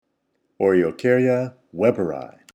Pronunciation/Pronunciación:
O-re-o-cár-ya wé-ber-i